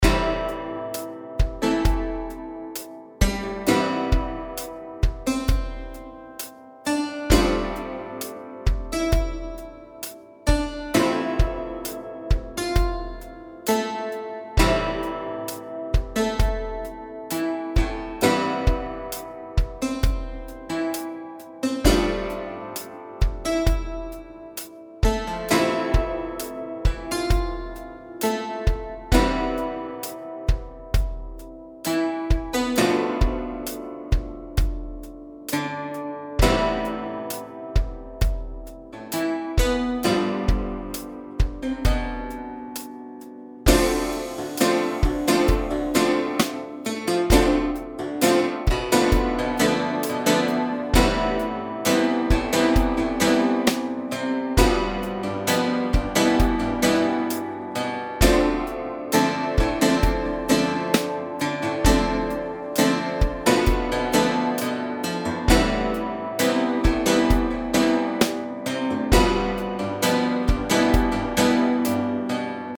Das Clavichord ist vom Klang her dem Cembalo verwandt und wurde erstmals um 1400 gebaut.
Das führt zu wunderbar schwebenden Klängen und ist aus ästhetischer Sicht zu begrüßen, dürfte aber nach den Beschreibungen, die ich gefunden habe, über die Möglichkeiten des Originals hinausgehen.
Zur Klanggestaltung bieten sich zwei mischbare Mikrofonierungen, Close und Room Mix, der bekannte und sehr effektive Color Shift sowie das Vibrato (on/off) an.
Hier hören Sie die Variante Folk mit den EZdrummer Pop und einem Pattern aus EZkeys Dream Pop (66 BPM):